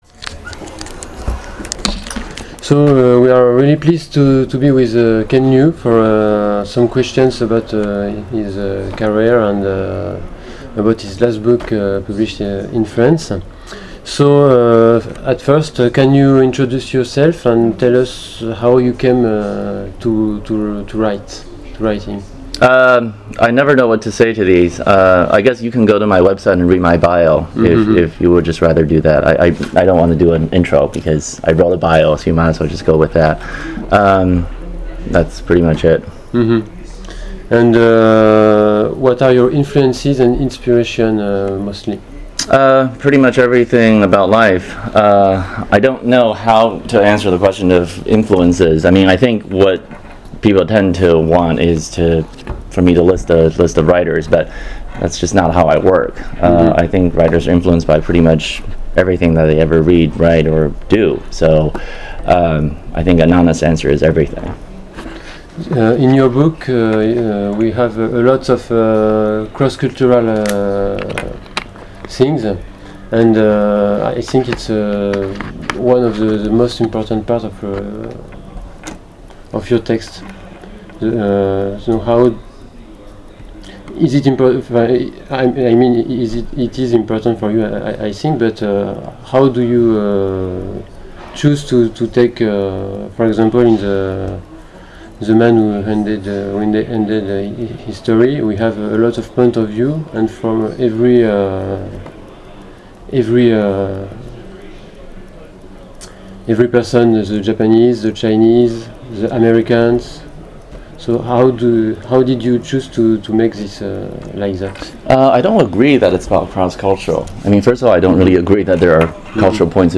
Interview 2016 : Ken Liu en France